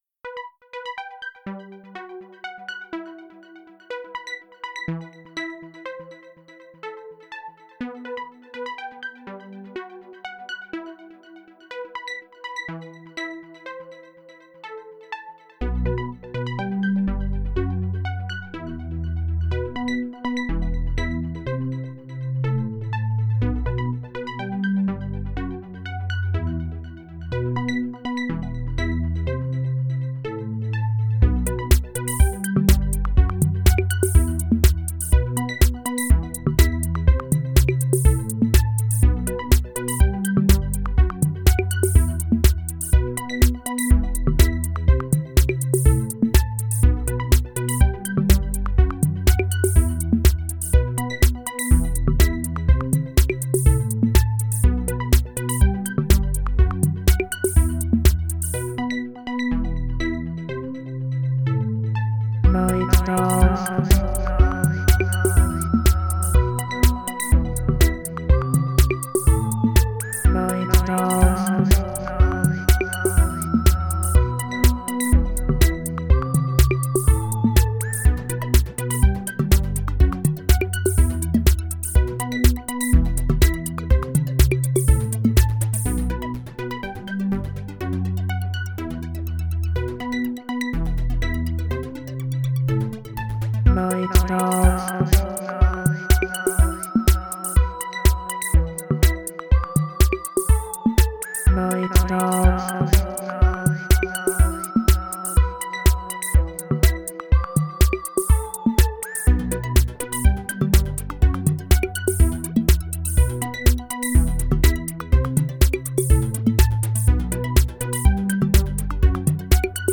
Genre: Techno.